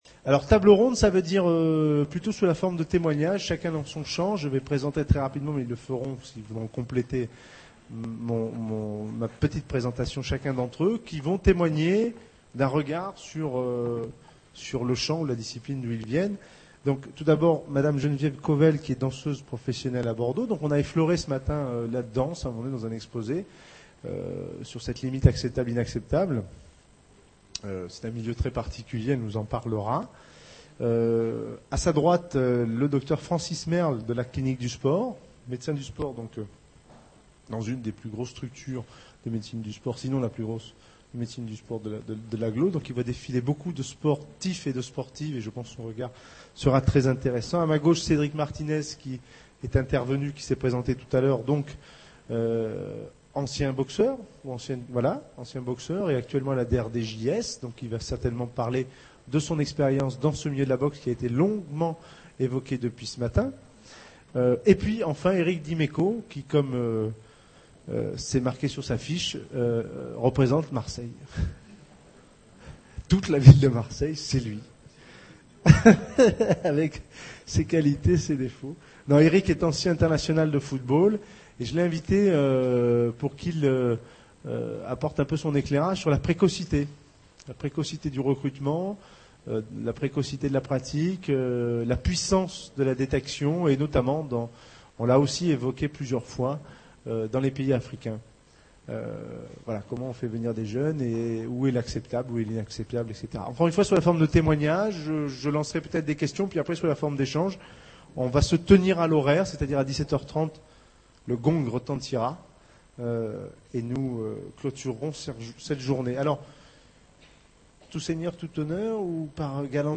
Maltraitance en lien direct avec la pratique sportive : Table Ronde | Canal U
Sport Intensif et Maltraitance - 3e journées de Psychopathologie du sport